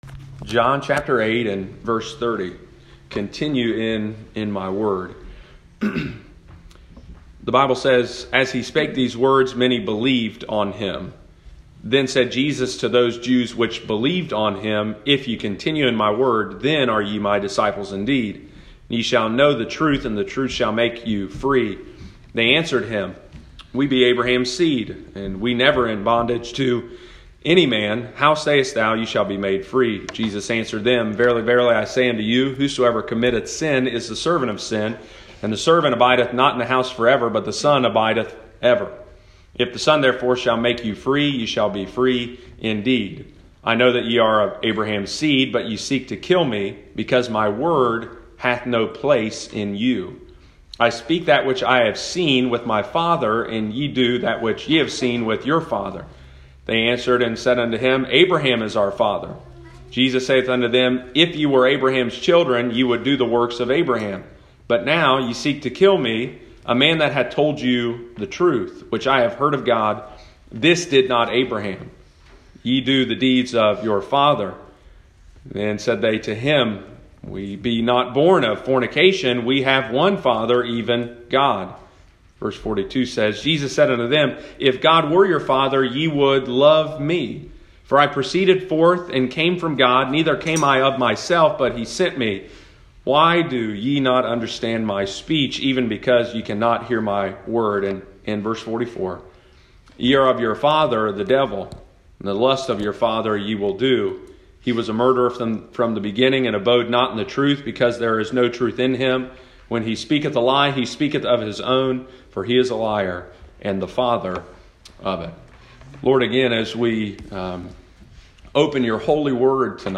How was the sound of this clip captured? Continue in His Word pt. 2 – Lighthouse Baptist Church, Circleville Ohio